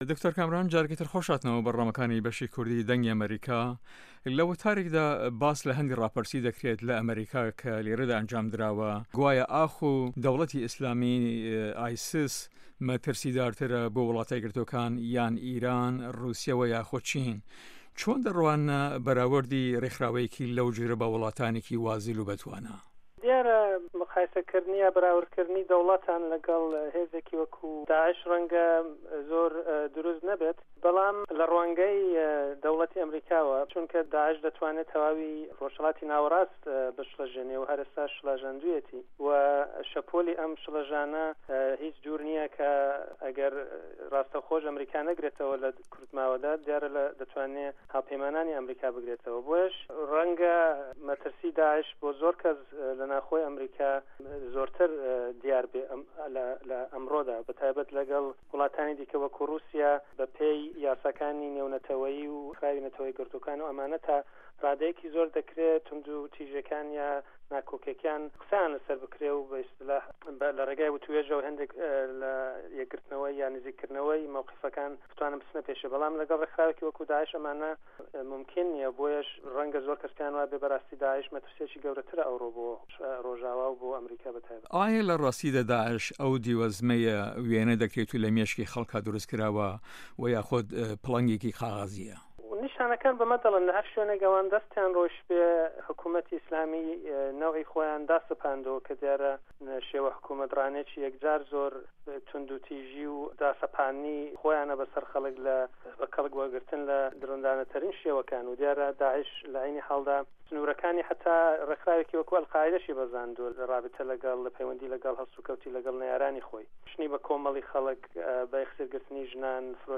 هه‌ڤپه‌یڤینێکدا